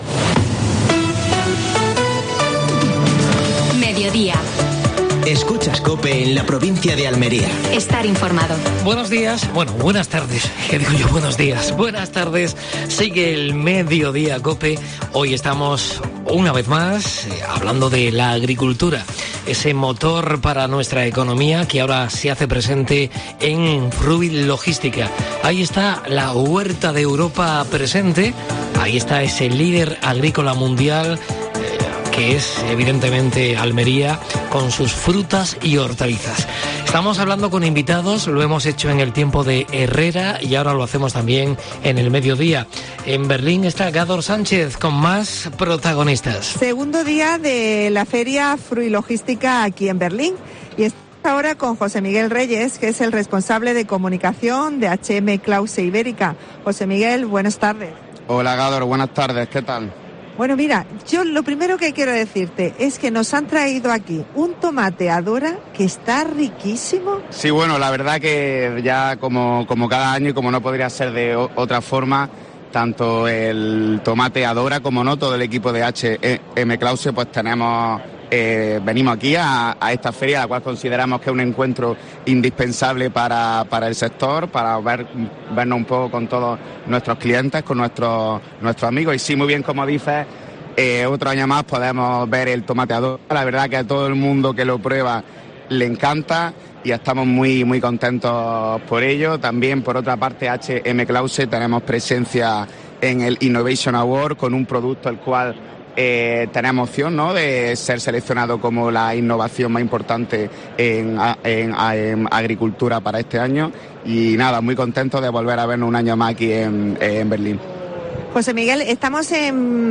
Deportes.